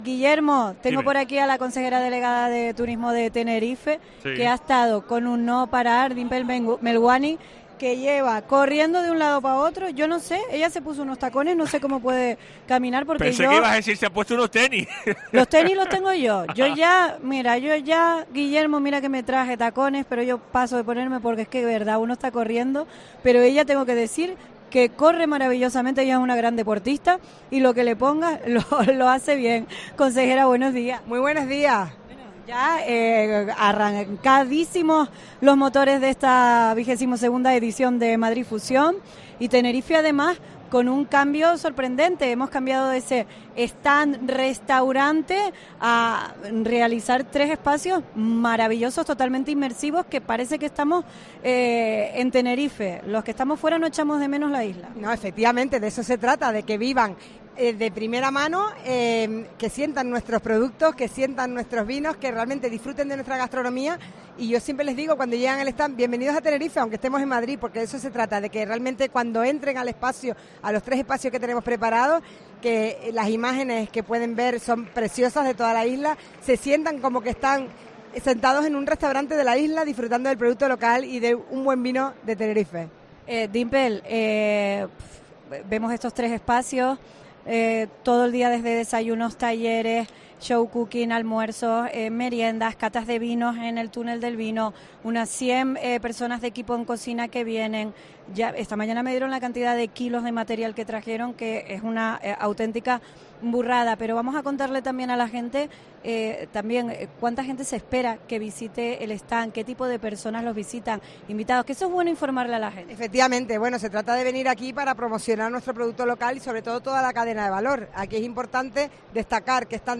Entrevista
en Madrid Fusión